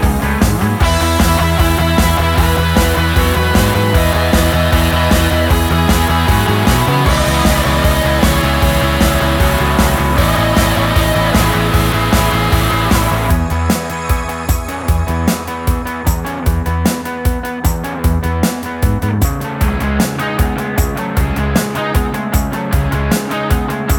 One Semitone Down Rock 3:24 Buy £1.50